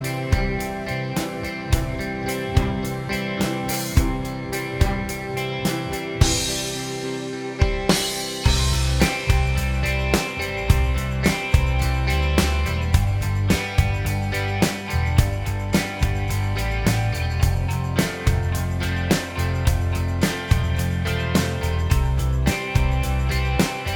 Minus Acoustic Guitar Pop (1990s) 3:31 Buy £1.50